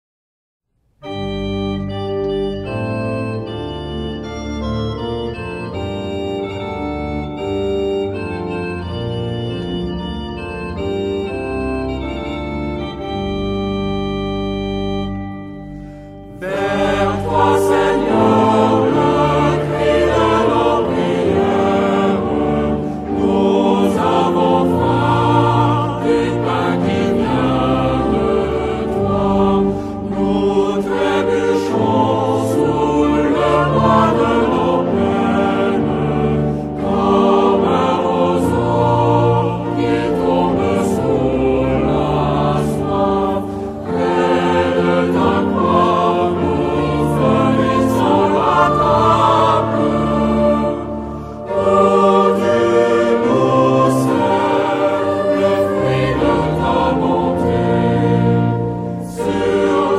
Genre-Style-Form: Sacred ; Motet
Type of Choir: SATB  (4 mixed voices )
Tonality: G major